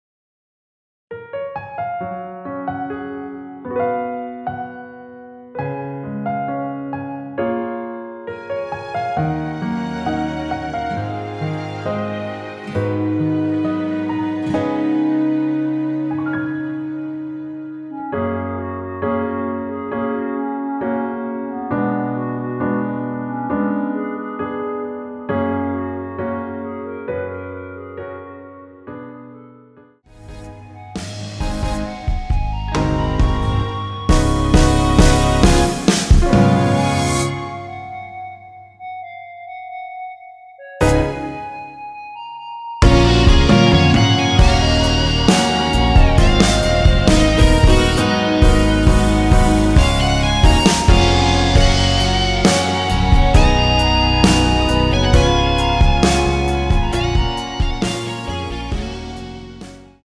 원키에서(-1)내린 멜로디 포함된 MR입니다.(미리듣기 확인)
Gb
앞부분30초, 뒷부분30초씩 편집해서 올려 드리고 있습니다.
중간에 음이 끈어지고 다시 나오는 이유는